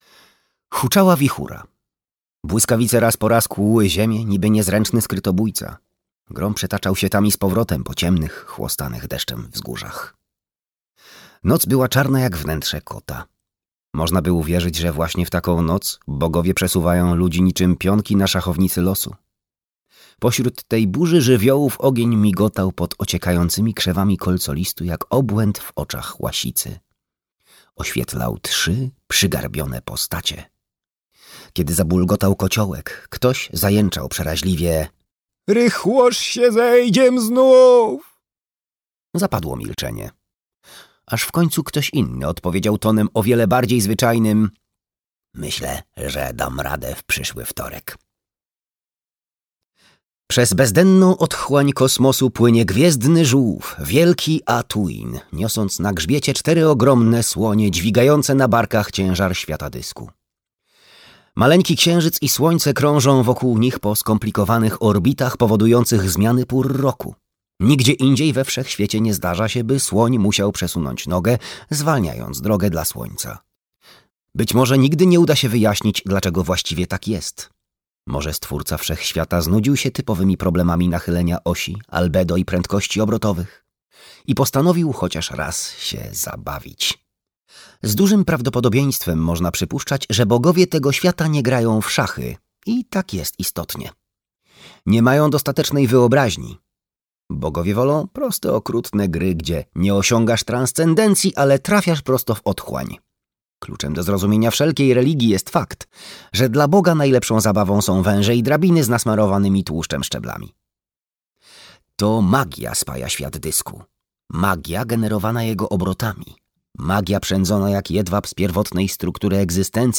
Trzy wiedźmy - Terry Pratchett - audiobook